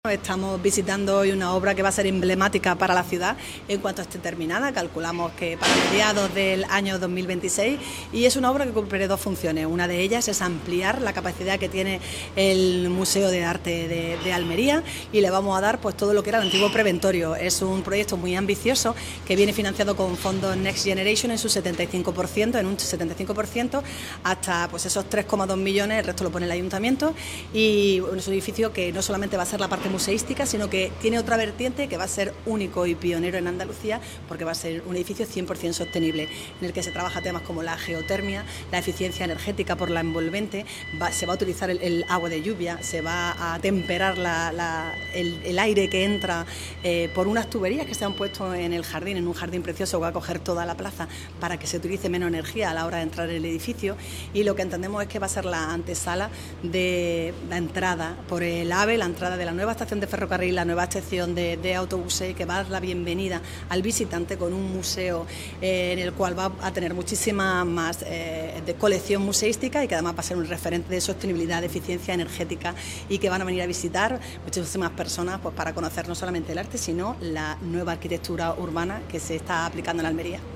CORTE-ALCALDESA-OBRAS-PREVENTORIO-MUSEO.mp3